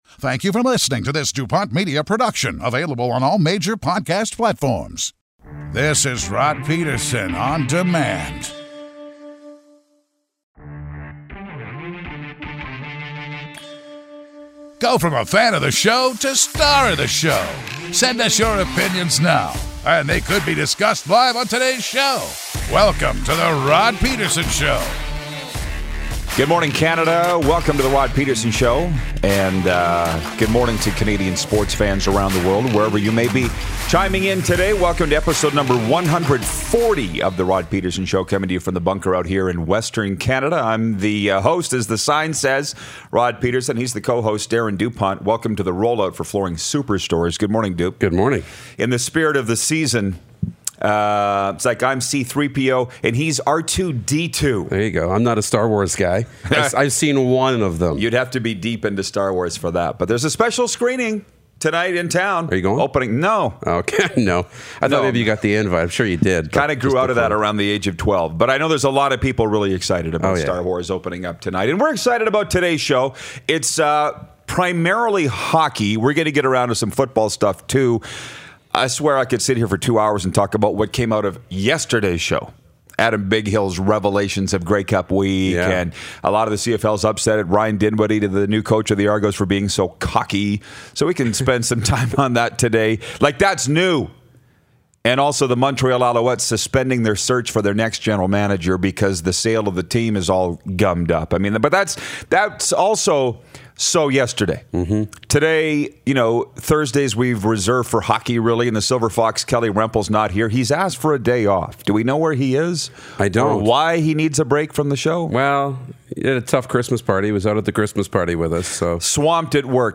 It’s beginning to look a lot like sports talk!